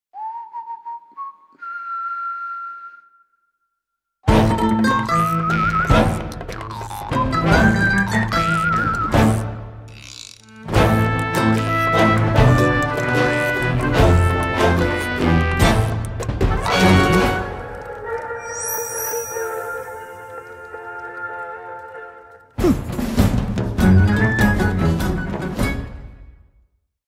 soundtrack
Trimmed to 27 seconds, with a fade out effect